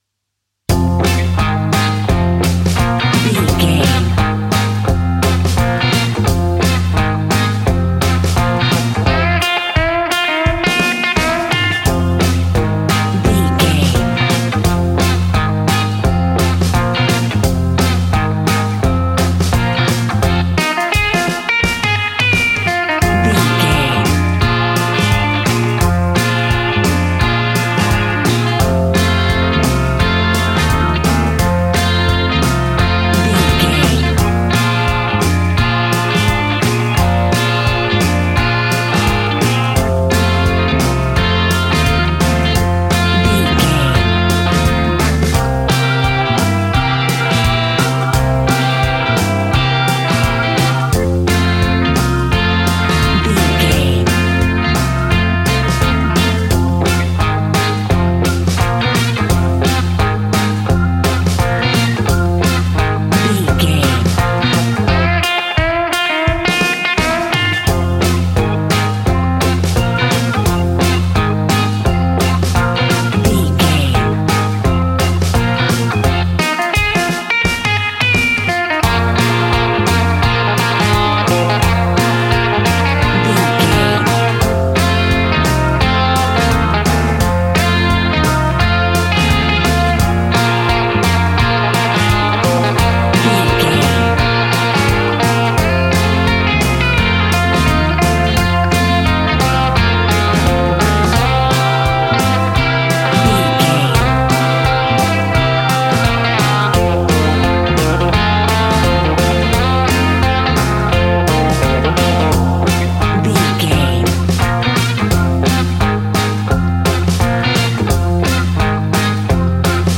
Ionian/Major
cool
uplifting
bass guitar
electric guitar
drums
cheerful/happy